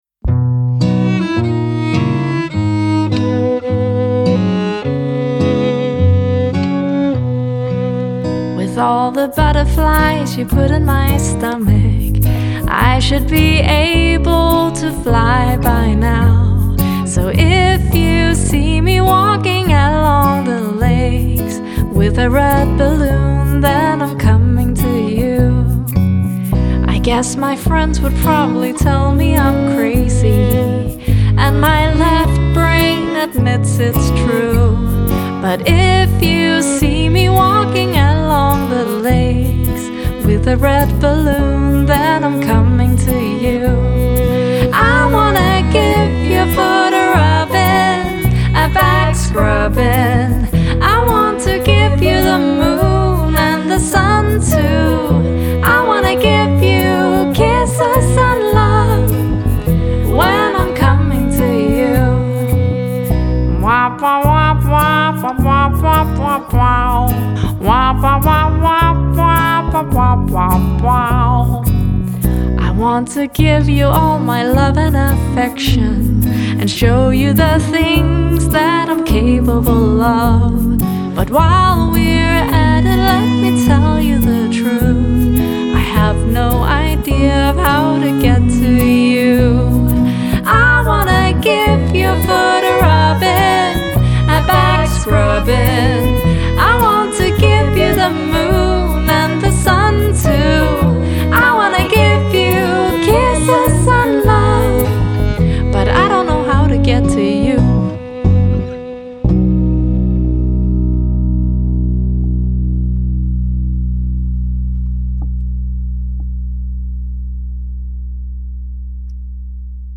• Folk
• Jazz
• Singer/songwriter
• Viser
Vokal